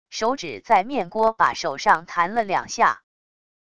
手指在面锅把手上弹了两下wav音频